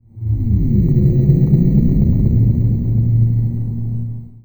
Theremin_Atmos_03.wav